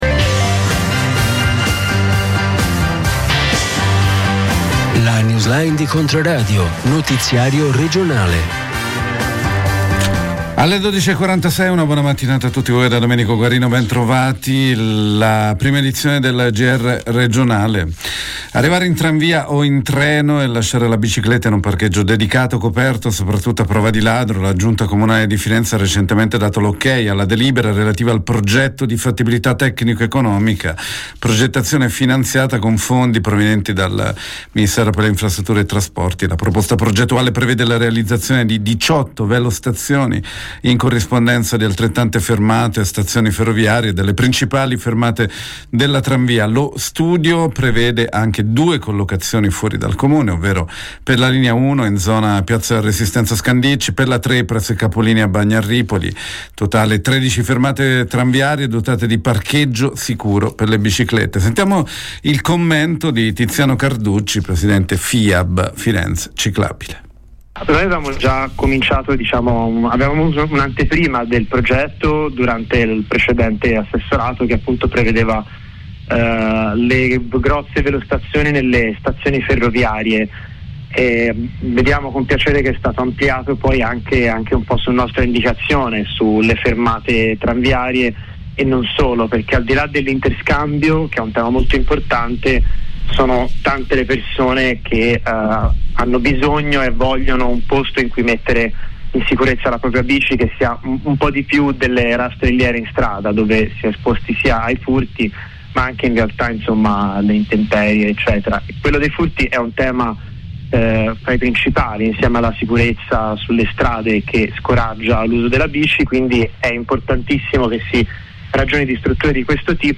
Notiziario regionale